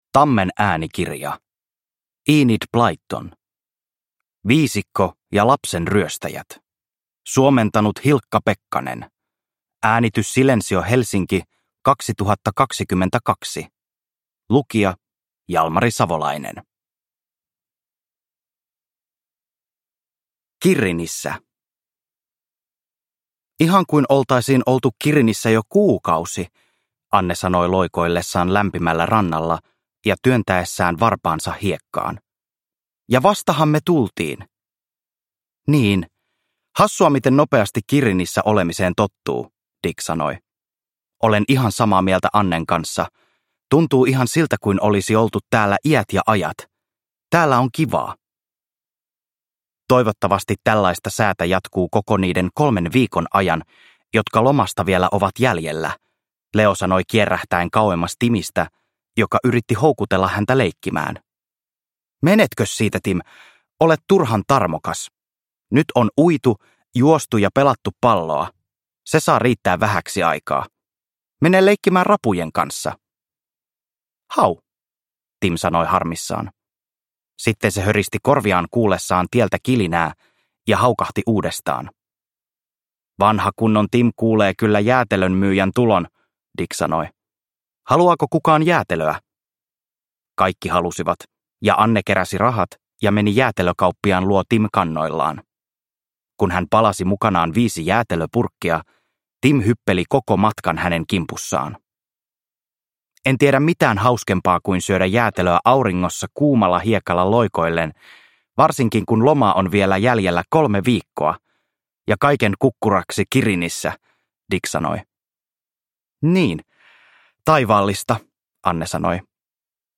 Viisikko ja lapsenryöstäjät – Ljudbok – Laddas ner